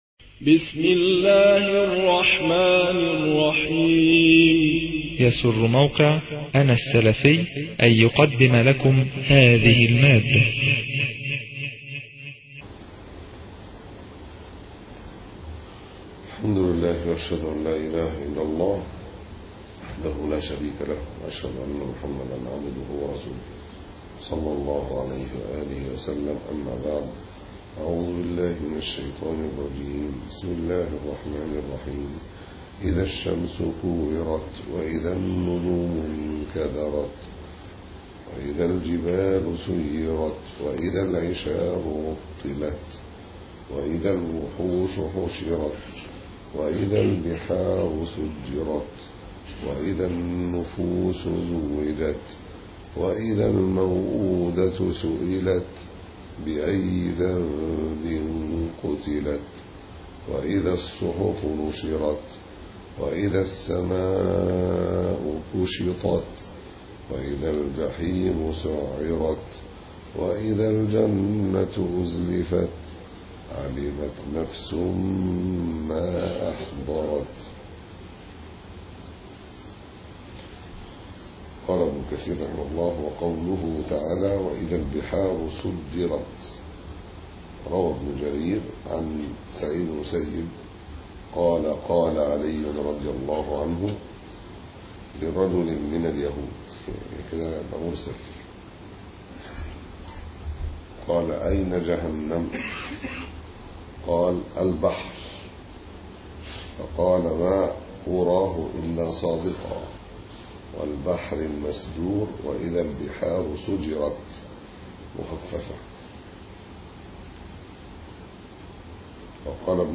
006- تفسير بن كثير - سورة التكوير(2) (معتكف 1433